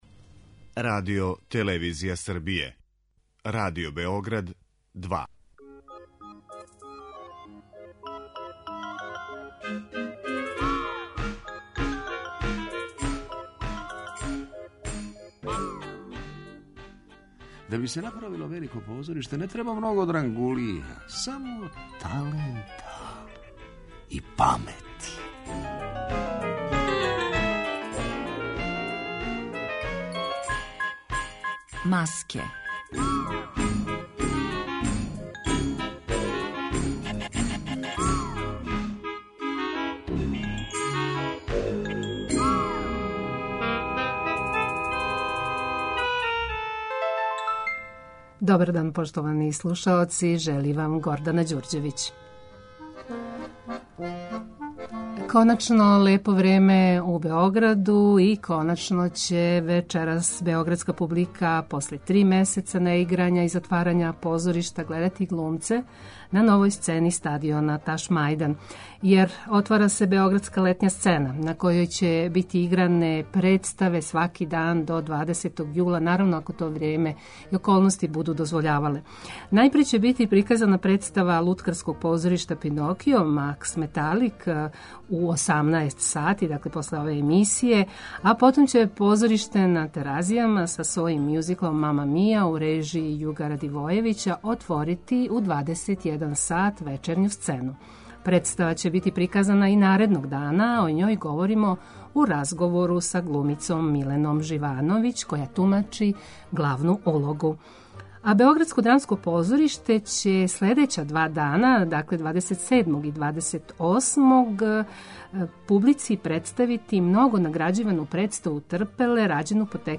Емисија посвећена позоришту